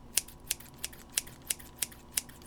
R - Foley 234.wav